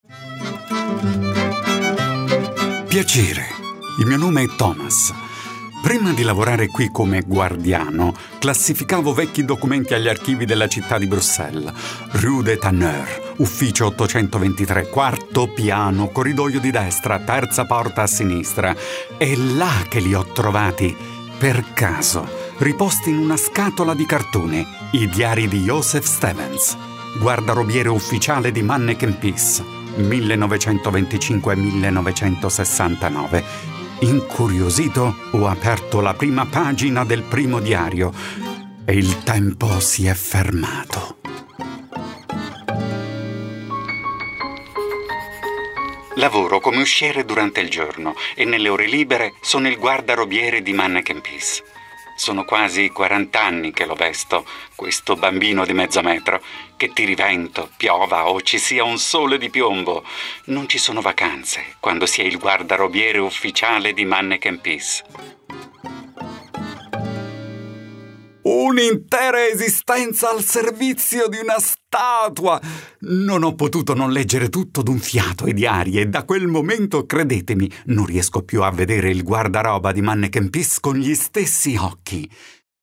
Sprecher italienisch.
Sprechprobe: Sonstiges (Muttersprache):
AudioGuide Museum Mannekenpis Bruxelles  2017.mp3